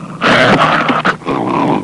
Dog Growl Sound Effect
Download a high-quality dog growl sound effect.
dog-growl.mp3